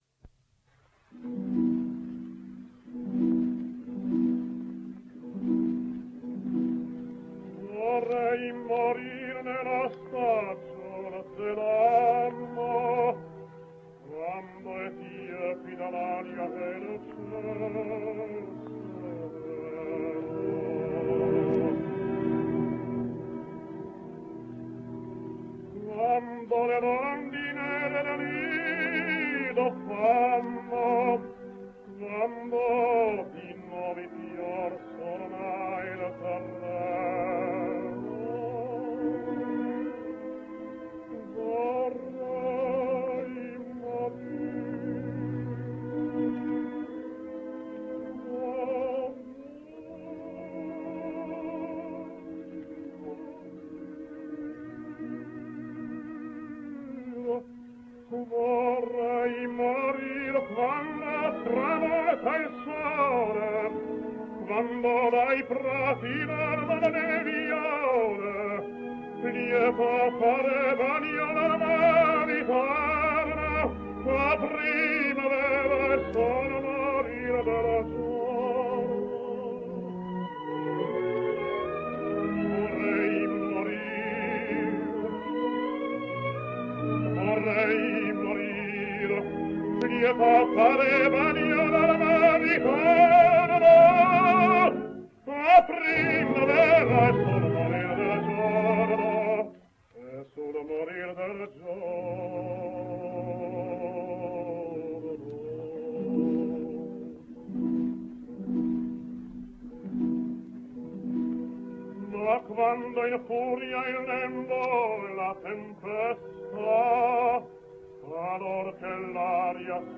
Italian Tenor